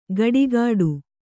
♪ gaḍigāḍu